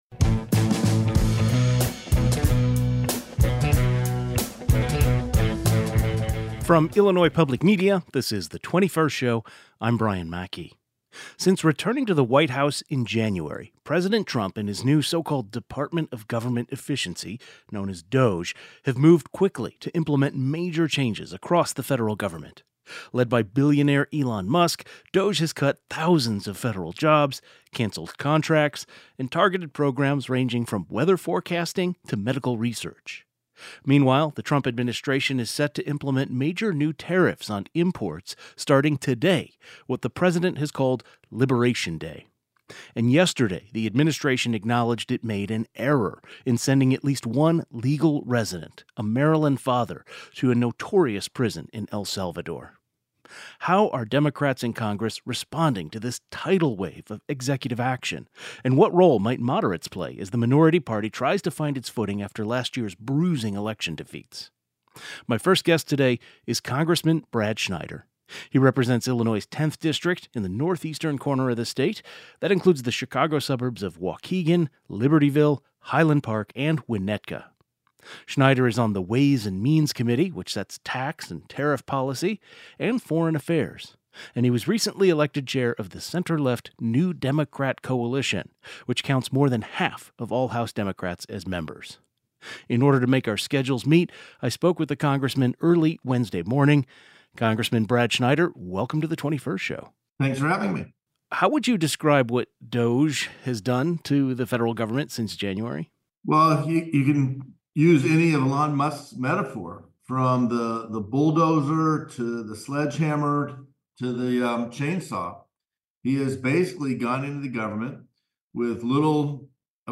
‘Increase public outcry’: Congressman Brad Schneider responds to DOGE cuts, Trump’s tariffs, and the latest in foreign affairs
Schneider offers his take on all the major changes happening in the goverment right now and how his fellow Democrats are responding. GUEST Congressman Brad Schneider Democrat , IL-10 Tags